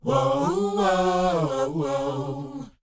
WHOA C#CD.wav